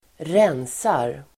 Uttal: [²r'en:sar]